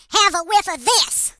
share/hedgewars/Data/Sounds/voices/HillBilly/Firepunch2.ogg
07d83af9d2a8 Add hillbilly voice